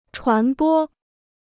传播 (chuán bō): to disseminate; to propagate; to spread.